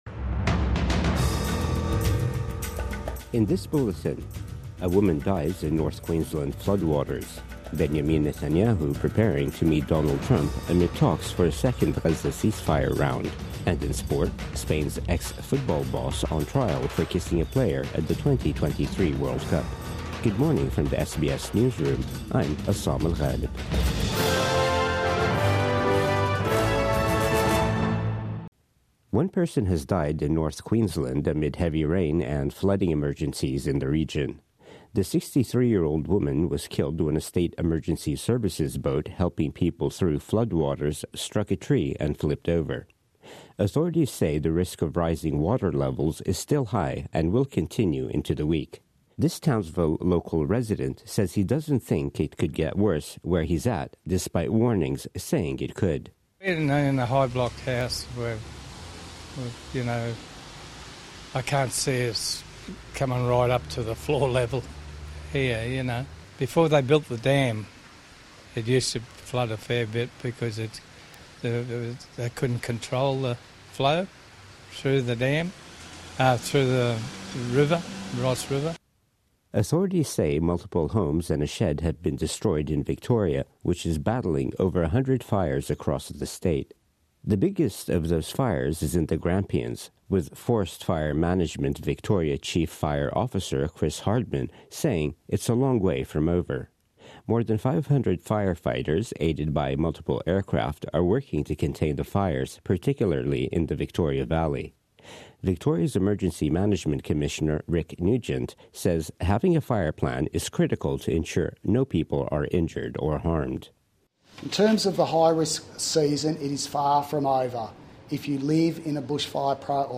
Morning News Bulletin 4 February 2025